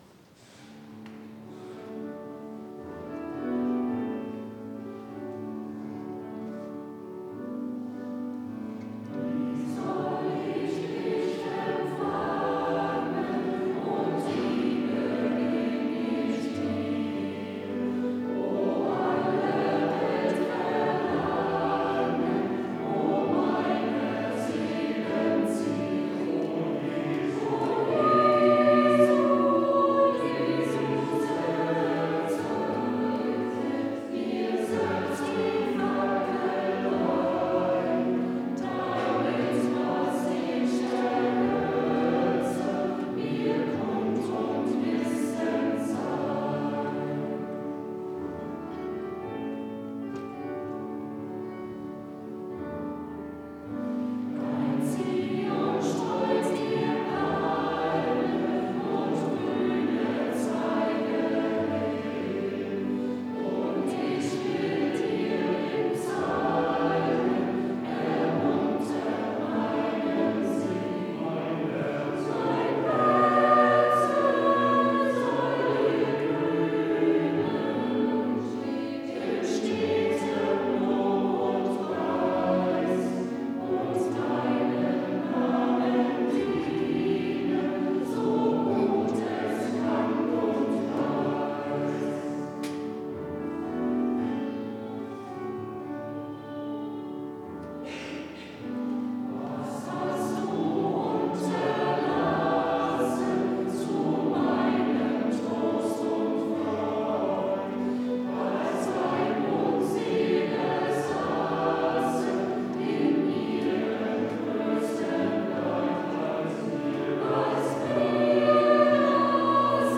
Wie soll ich dich empfangen? Chor der Ev.-Luth. St. Johannesgemeinde (Zwickau-Planitz)
Audiomitschnitt unseres Gottesdienstes vom 4.Advent 2025.